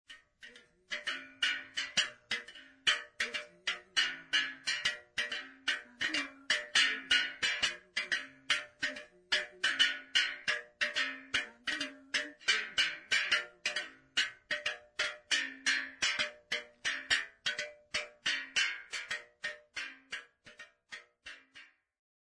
Folk songs, Xhosa South Africa
Stringed instrument music South Africa
field recordings
Traditional song accompanied by the Xhosa Mrhube bow performed for Rhodes University Symposium.